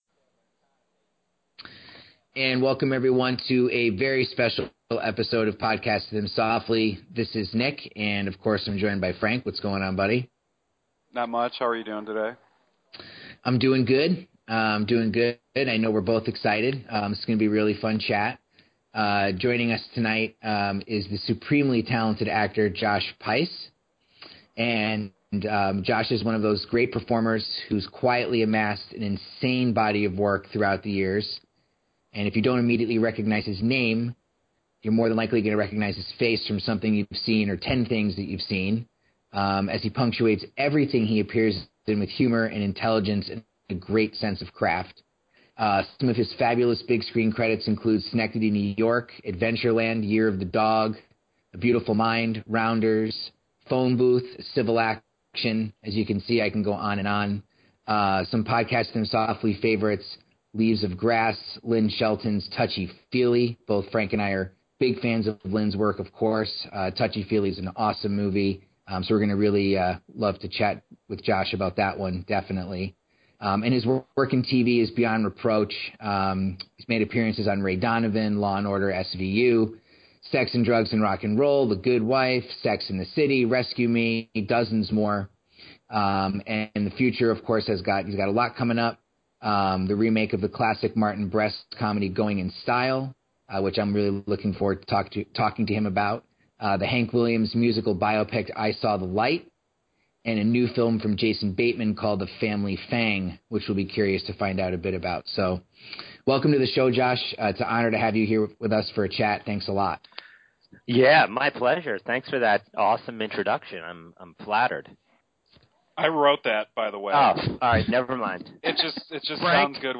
Podcasting Them Softly is honored to present a chat with actor Josh Pais!